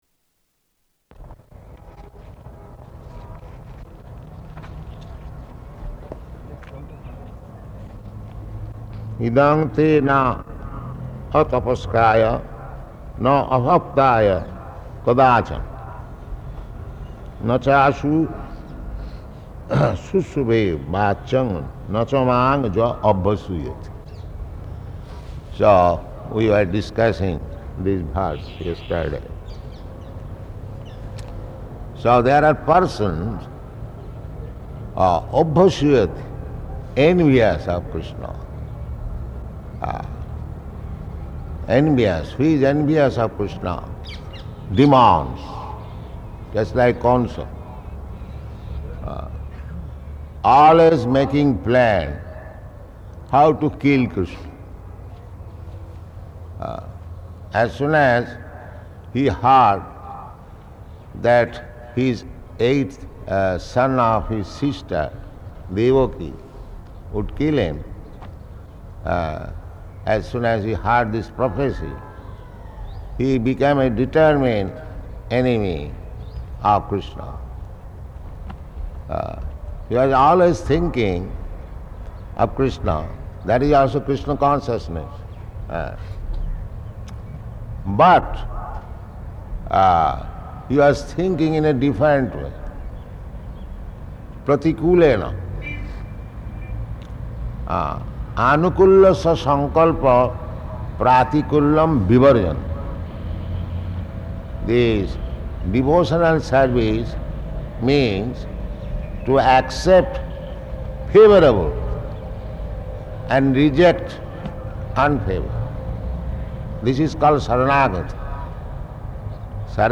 Location: Ahmedabad